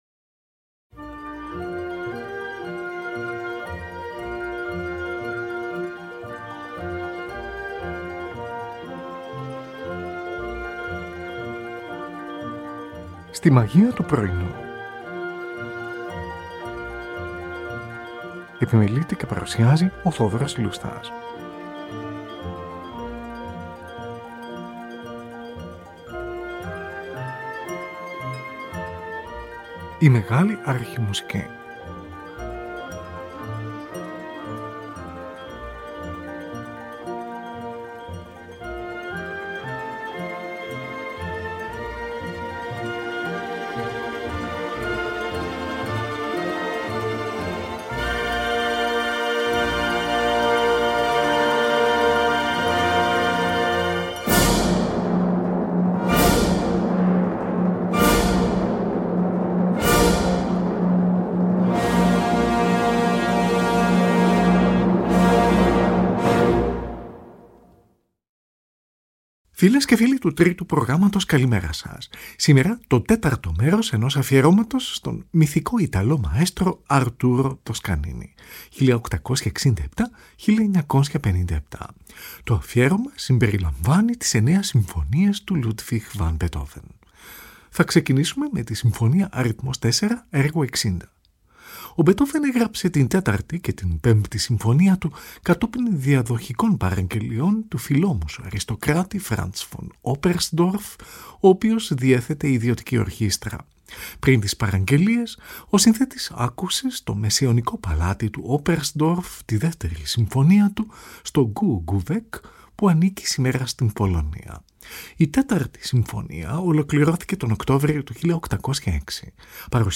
Tη Συμφωνική του NBC διευθύνει ο Arturo Toscanini. Zωντανή ραδιοφωνική μετάδοση, στις 4 Νοεμβρίου 1939.
Zωντανή ραδιοφωνική μετάδοση, στις 18 Νοεμβρίου 1939.